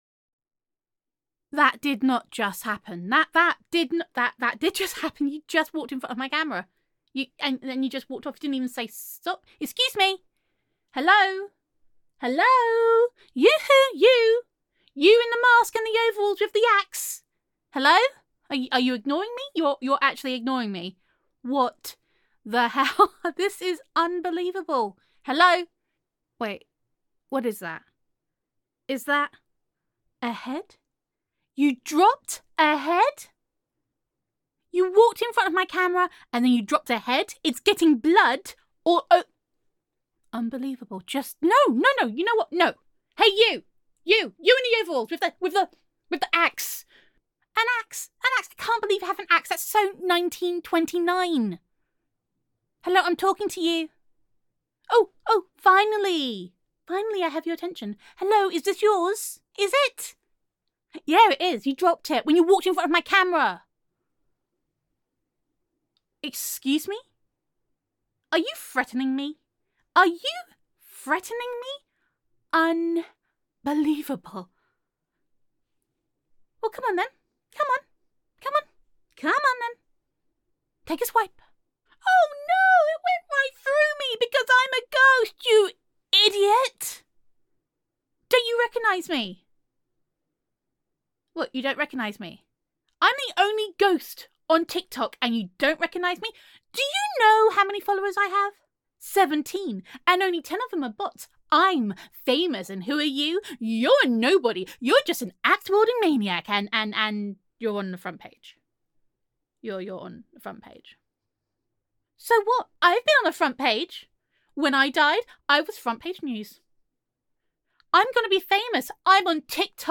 [F4A] Over Exposed [Tik Tok Interrupted][You Dropped Your Corpse Head][I Am Internet Famous][Ghost Roleplay][Clout Chasing][Gender Neutral][An Axe Wielding Maniac Interrupts a Wannabe Tik Tok Star]